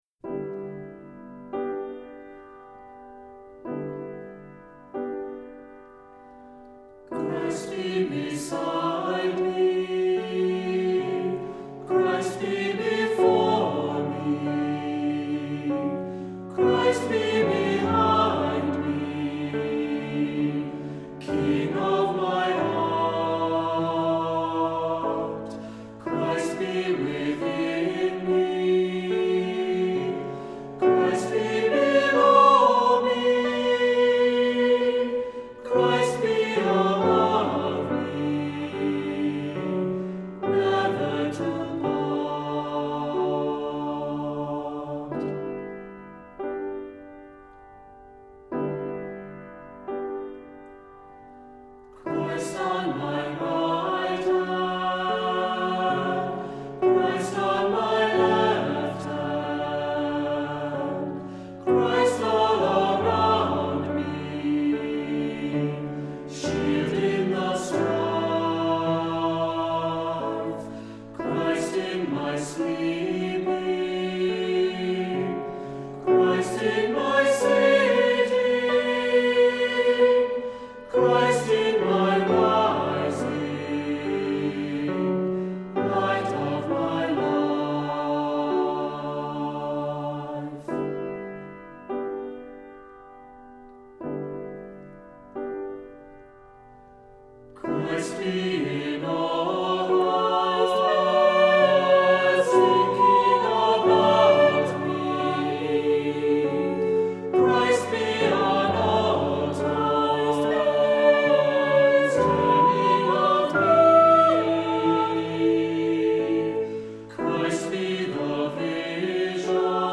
Voicing: Unison with descant; Assembly